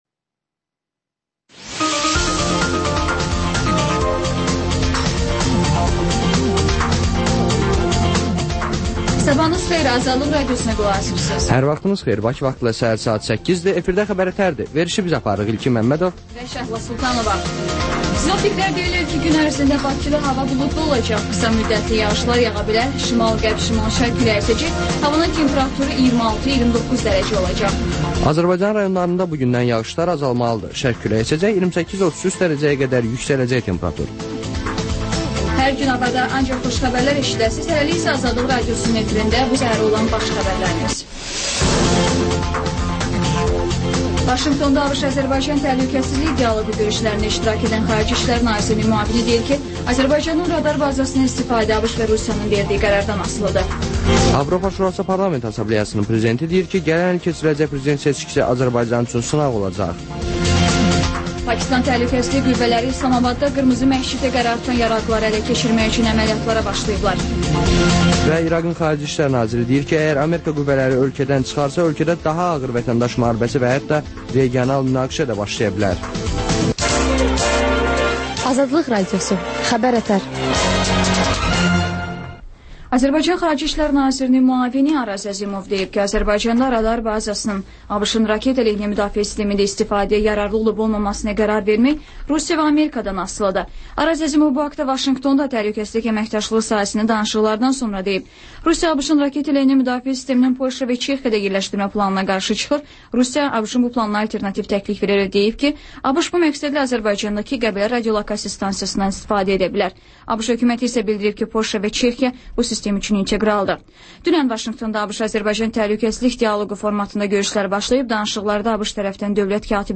Xəbər-ətər: xəbərlər, müsahibələr, sonda XÜSUSİ REPORTAJ rubrikası: Ölkənin ictimai-siyasi həyatına dair müxbir araşdırmaları